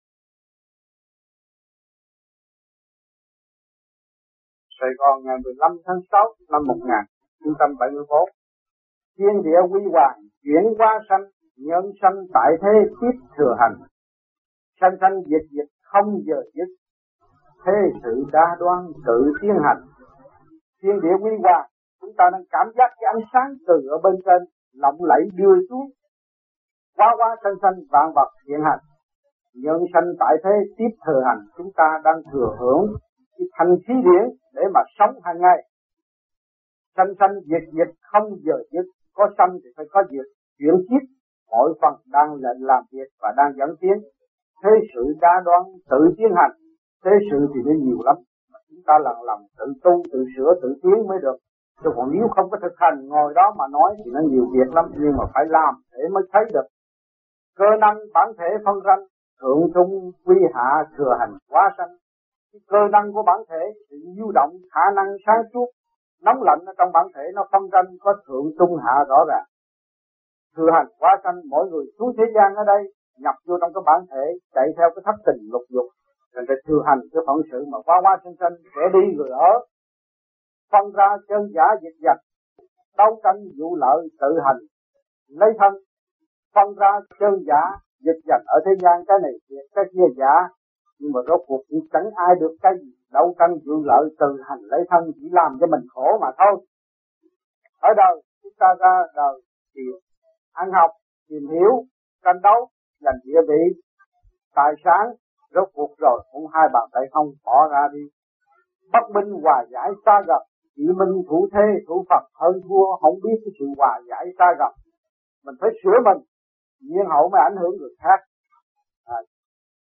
Mẫu Ái Giảng Tại Việt Nam